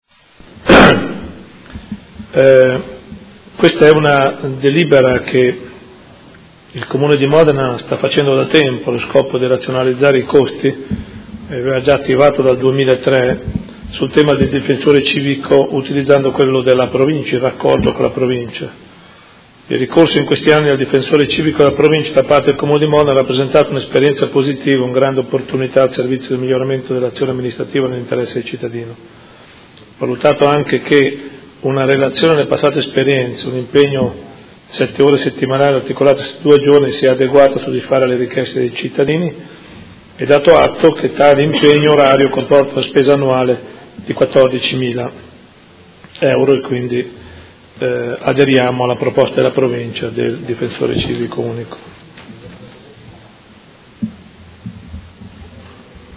Gian Carlo Muzzarelli — Sito Audio Consiglio Comunale